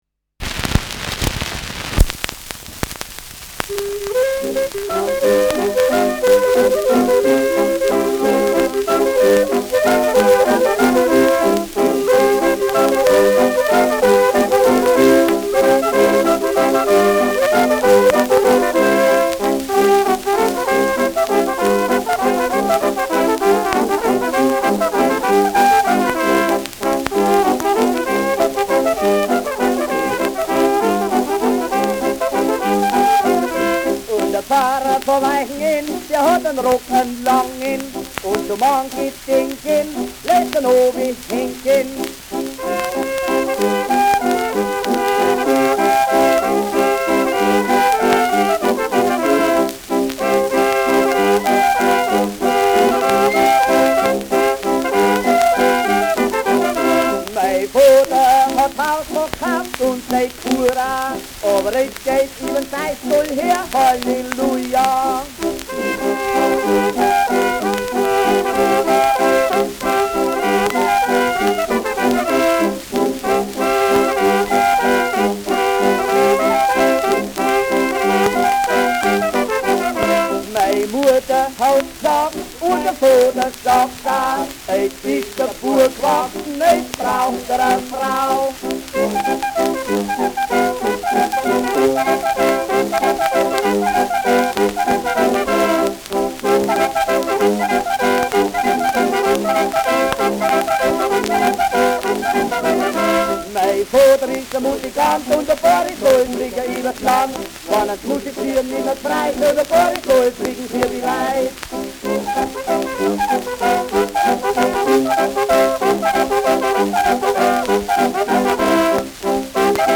Lustige Gstanzln : Ländler mit Gesang
Schellackplatte
Tonrille: Kratzer Durchgehend Leicht
Abgespielt : Vereinzelt leichtes Knacken